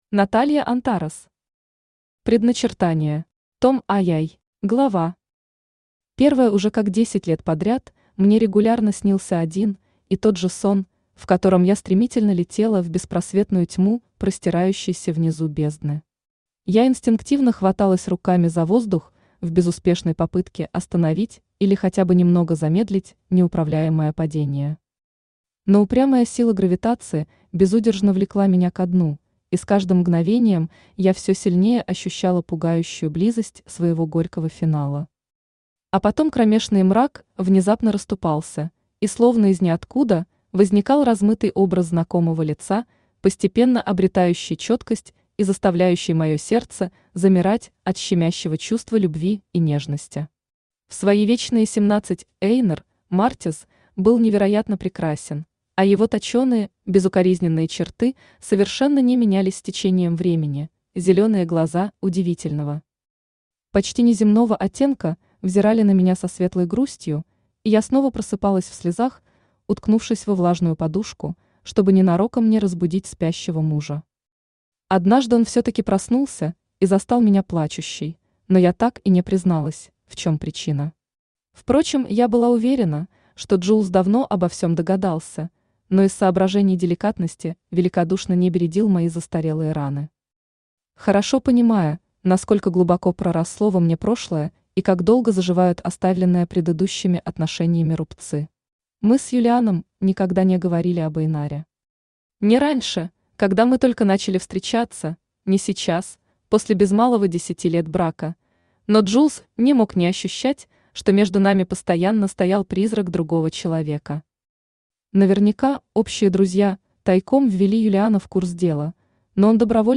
Аудиокнига Предначертание. Том II | Библиотека аудиокниг
Том II Автор Наталья Антарес Читает аудиокнигу Авточтец ЛитРес.